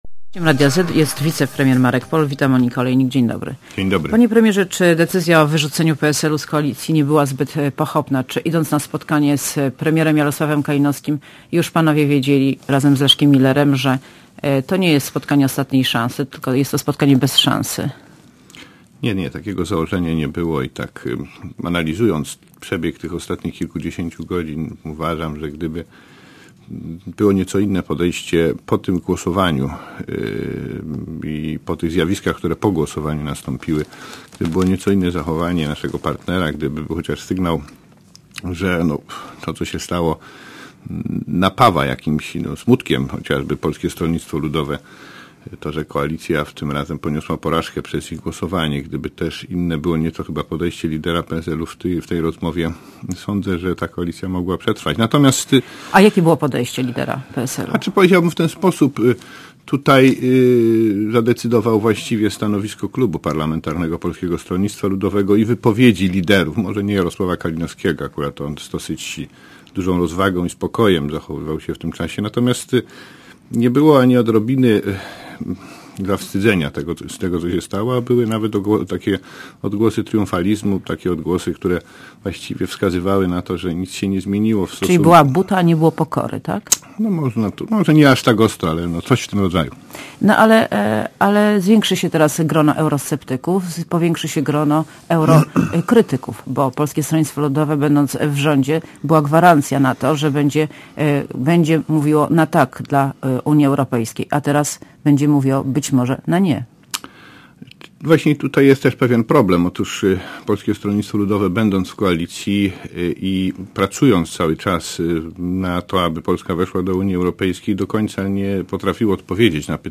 Monika Olejnik rozmawia z wiecepremierem Markiem Polem (UP)
© (RadioZet) © (RadioZet) Posłuchaj wywiadu (5,6MB) Panie Premierze, czy decyzja o wyrzuceniu PSL z koalicji nie była zbyt pochopna?